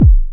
07_Kick_05_SP.wav